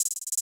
Hihat (Drumma-Roll).wav